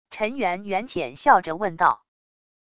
陈圆圆浅笑着问道wav音频生成系统WAV Audio Player